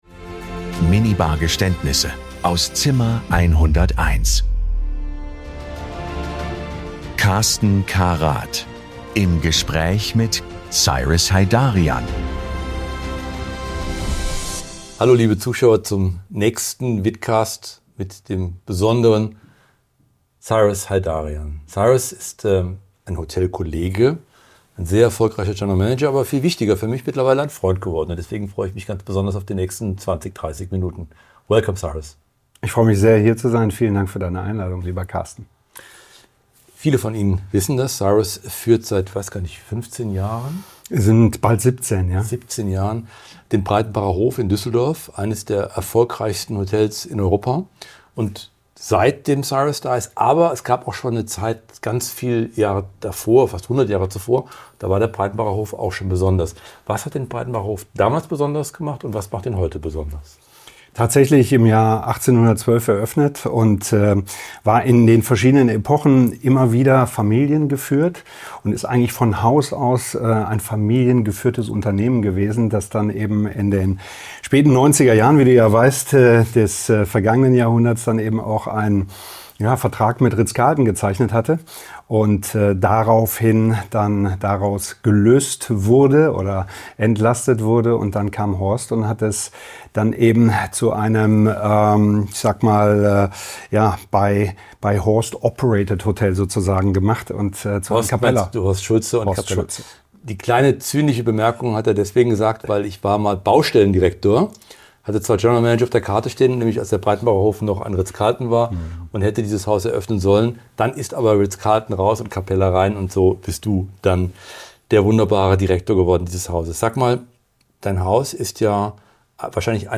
Es wird gelacht, reflektiert und manchmal auch gestanden.
Ein Gespräch über kluge Entscheidungsfindung, Verantwortung und exzellente Führung. Über Haltung, Konsequenz und die Frage, wie moderne Luxushotellerie heute gelebt wird.